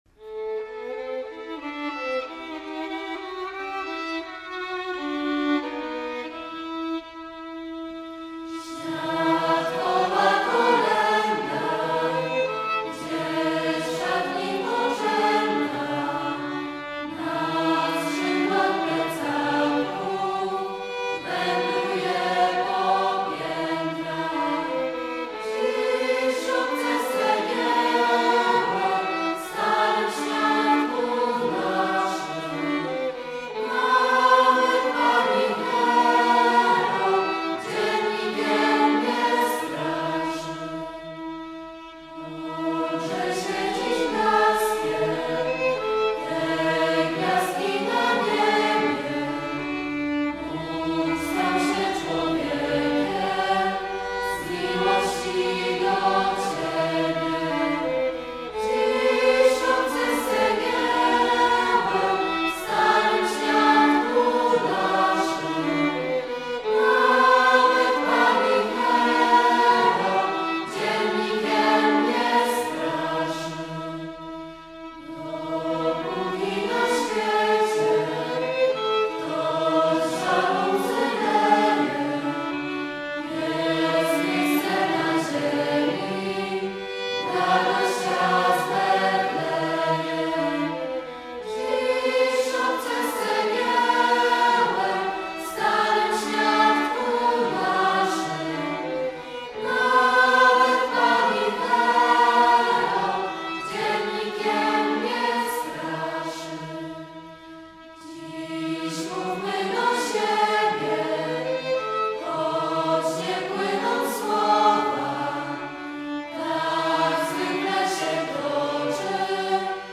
materiały tu zamieszczone są nagrane S Z K O L E N I O W O więc proszę się nie dopatrywać walorów artystycznych...
1, 2, 3 głos (czyli unisono),